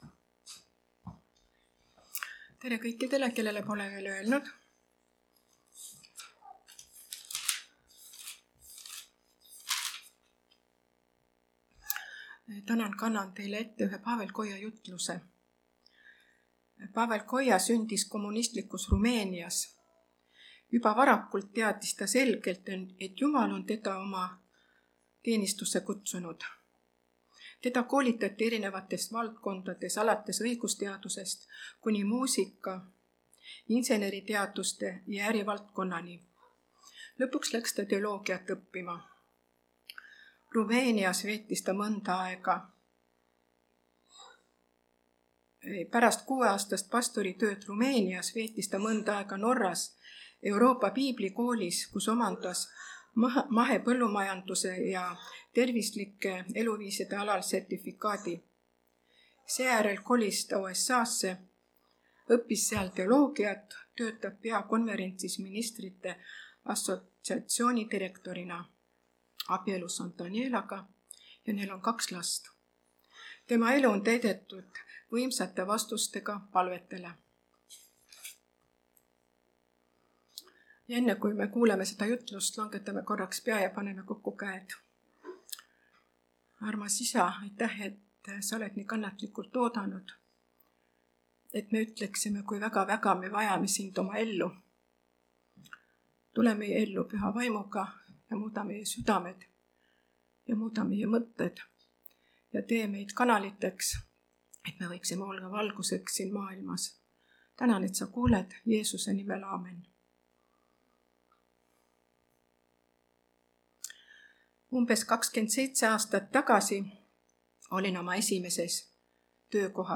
(Võrus)
Jutlused